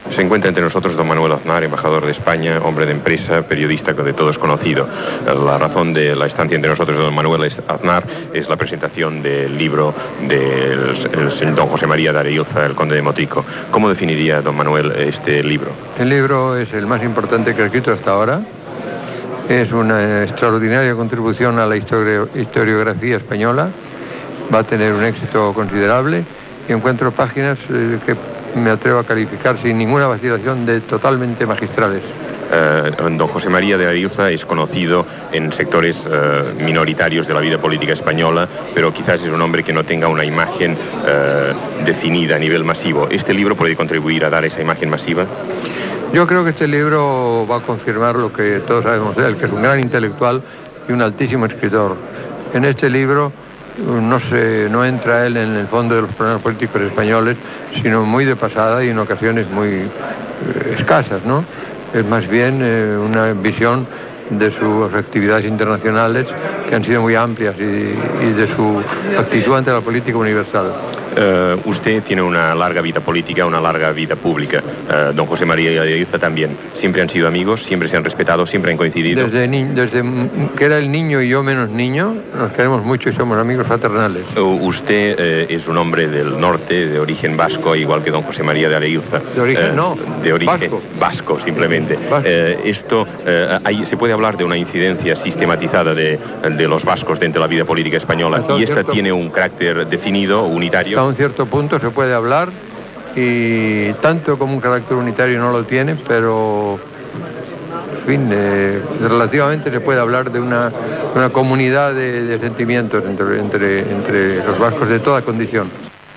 Entrevista a Manuel Aznar, ambaixador, que parla del llibre escrit per José María de Areilza, Conde de Motrijo i ambaixador.
Informatiu